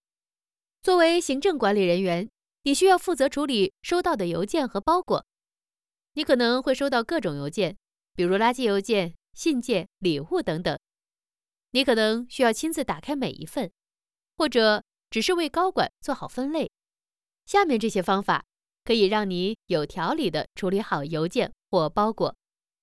Chinese_Female_044VoiceArtist_2Hours_High_Quality_Voice_Dataset
Text-to-Speech